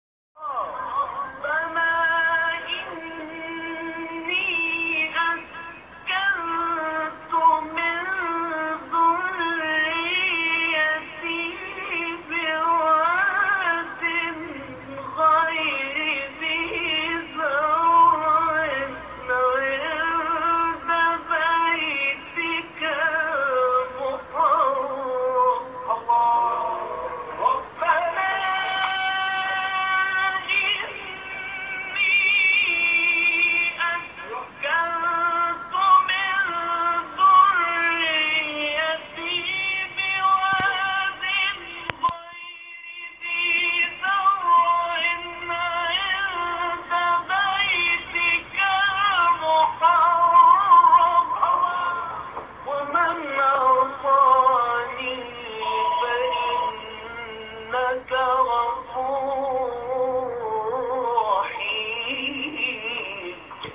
مقام حجاز.m4a
مقام-حجاز.m4a